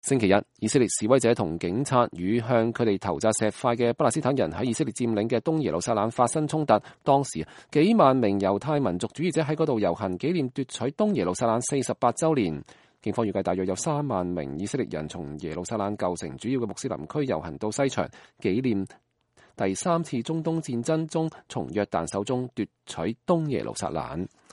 以色列示威者載歌載舞，高呼‘以色列萬歲’，而部份巴勒斯坦人則揮舞著巴勒斯